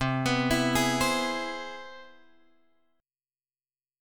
C Augmented Major 7th